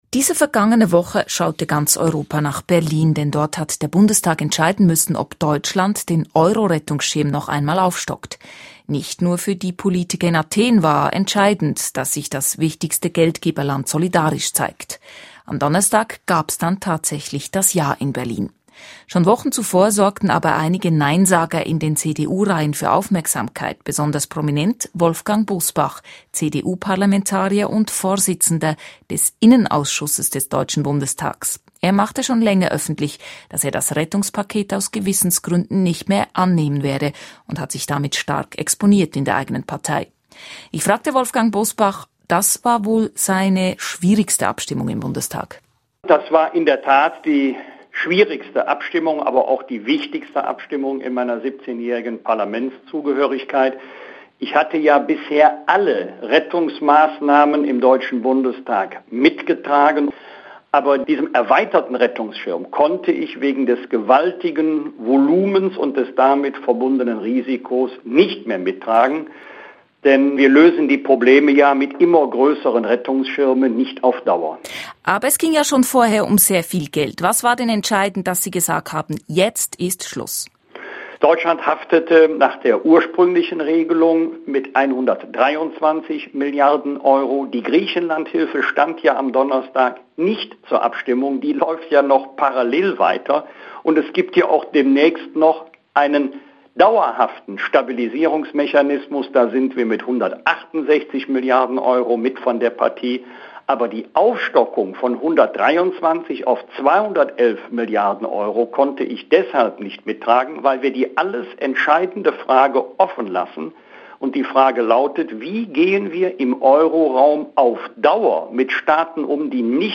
Dazu ein Gespräch mit Bosbach.